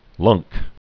(lŭngk)